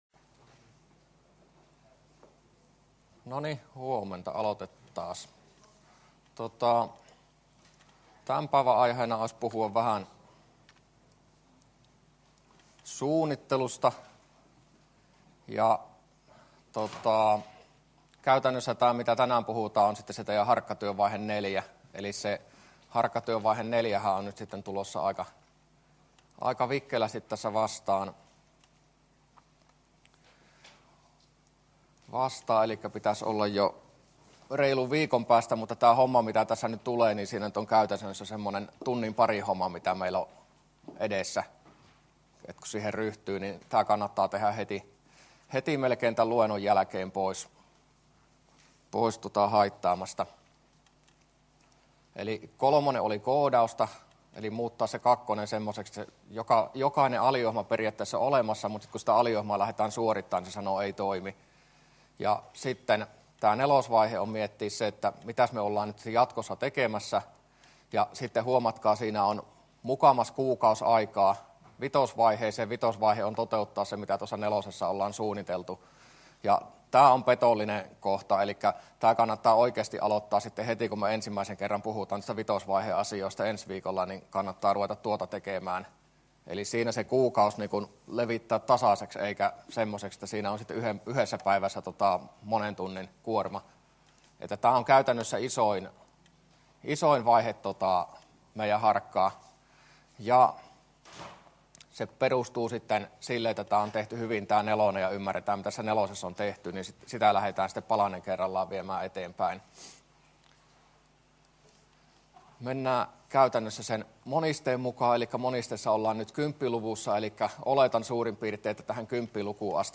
luento11a